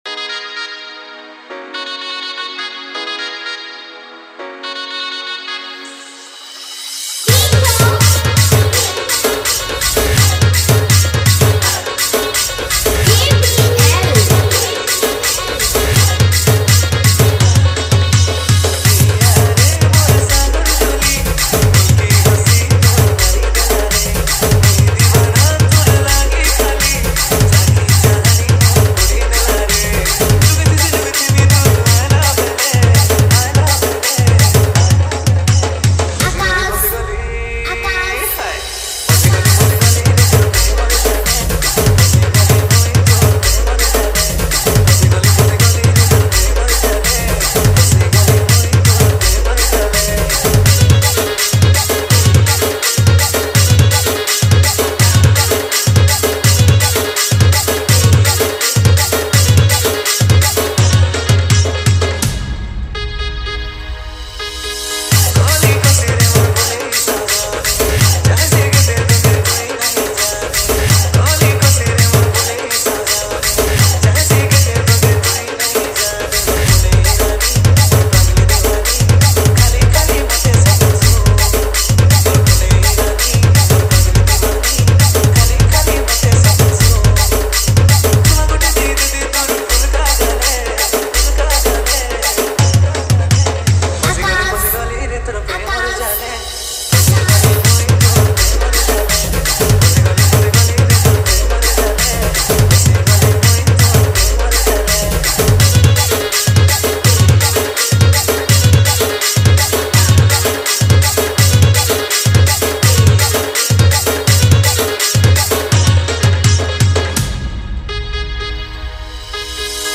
Category:  Sambalpuri Dj Song 2025